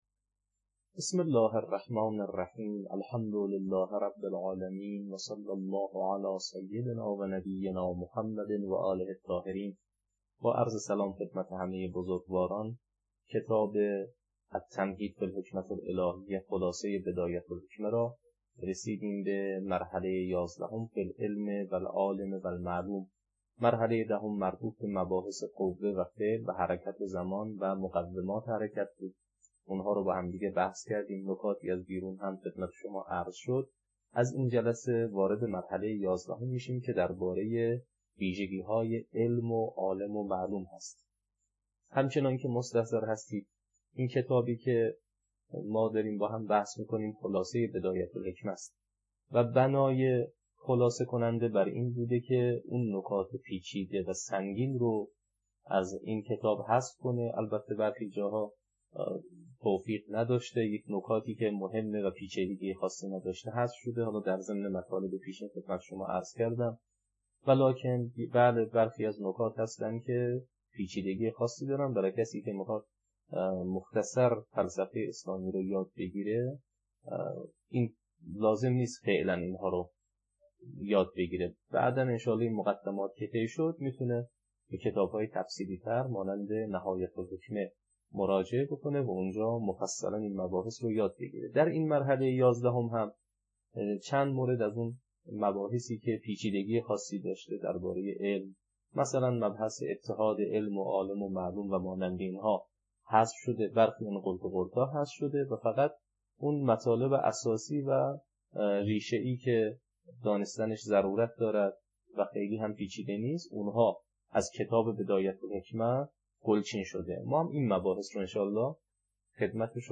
التمهيد في الحكمة الهية (خلاصه بدایه الحکمه) - تدریس